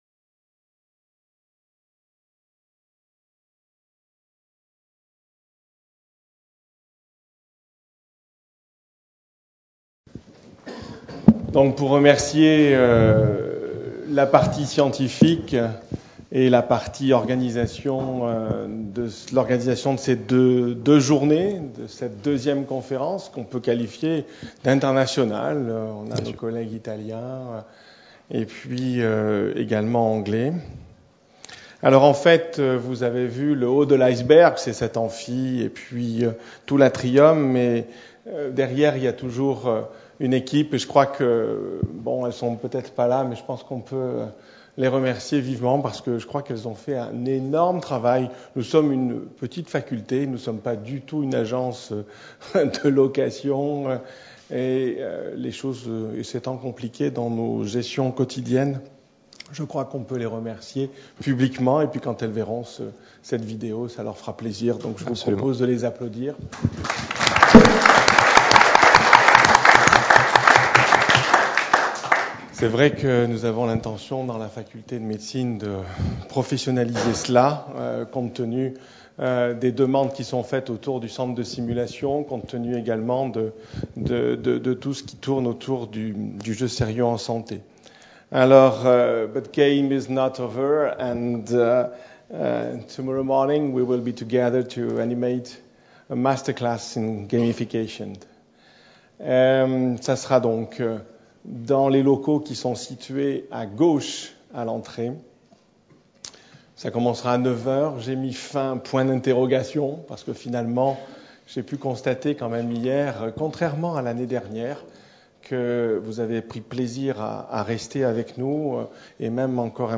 Conférence enregistrée dans le cadre de la 2ème conférence nationale sur l’usage des jeux sérieux dans l’enseignement et l’apprentissage en médecine et en santé (SeGaMed 2013) à Nice les 12 et 13 septembre 2013.